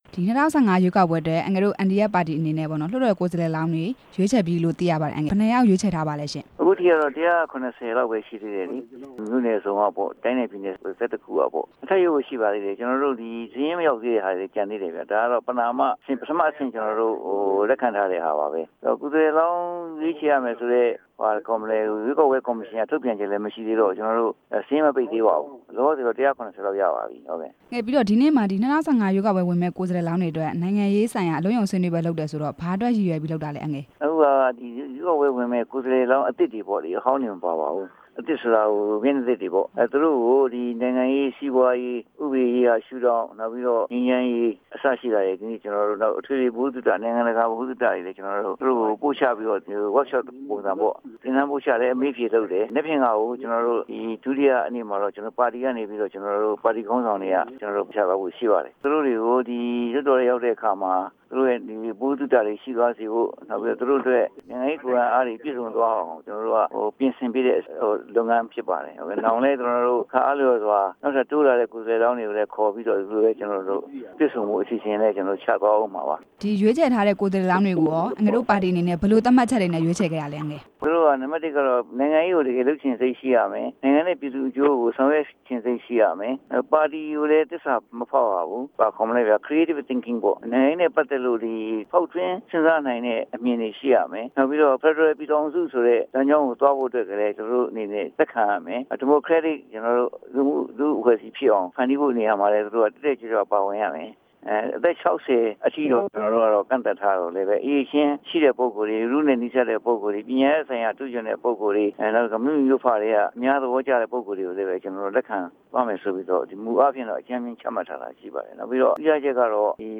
ဆက်သွယ်မေး မြန်းထားပါတယ်။